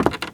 High Quality Footsteps
Wood, Creaky
STEPS Wood, Creaky, Walk 30.wav